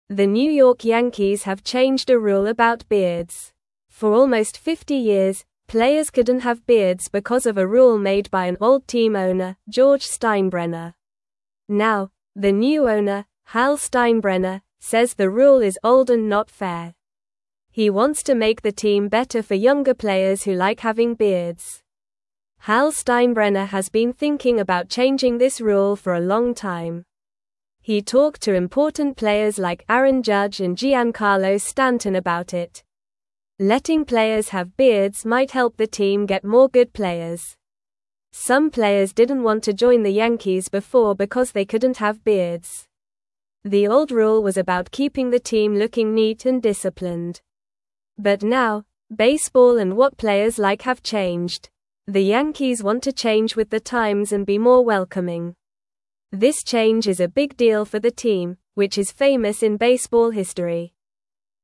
Normal
English-Newsroom-Lower-Intermediate-NORMAL-Reading-Yankees-Players-Can-Now-Have-Beards-Again.mp3